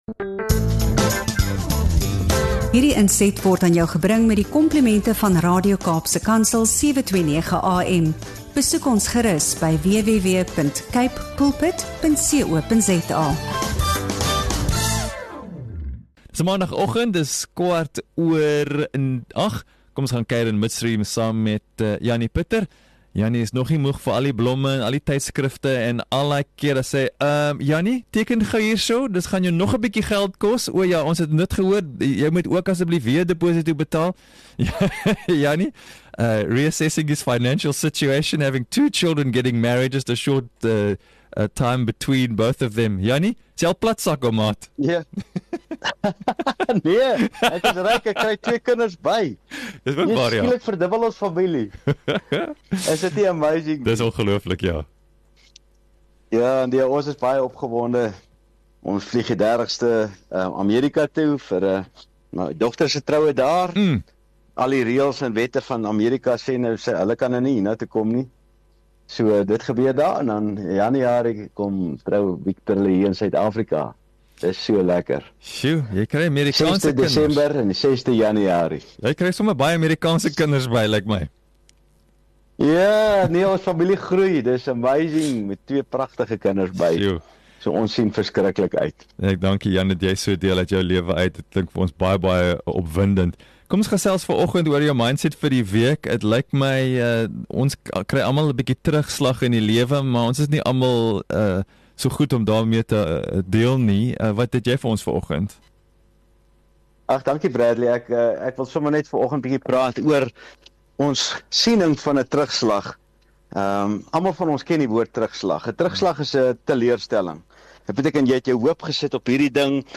In hierdie inspirerende gesprek op Radio Kaapse Kansel